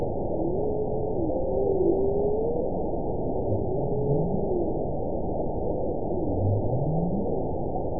event 921832 date 12/19/24 time 09:48:48 GMT (11 months, 2 weeks ago) score 9.67 location TSS-AB02 detected by nrw target species NRW annotations +NRW Spectrogram: Frequency (kHz) vs. Time (s) audio not available .wav